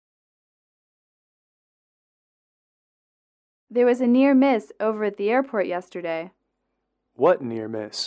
英语口语情景短对话24-1：机场事故(MP3)